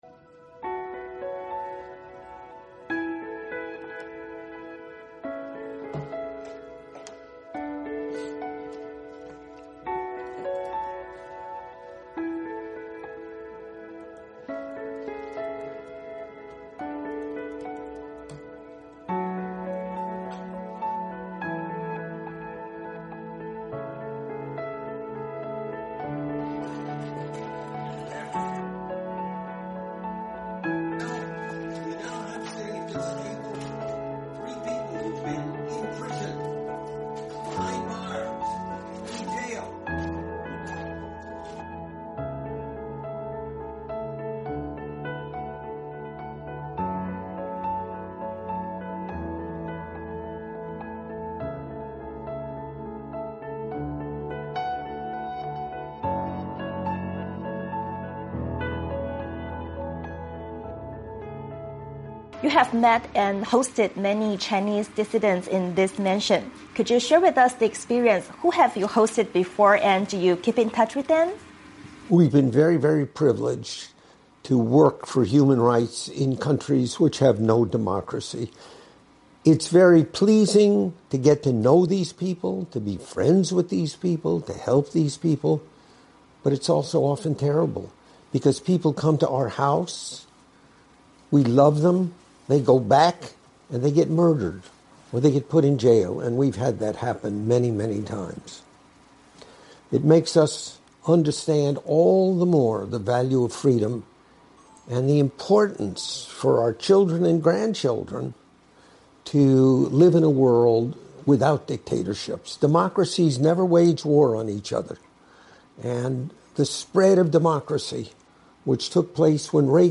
VOA专访: “民主力量终将使独裁垮台”：专访人权活动家霍洛维茨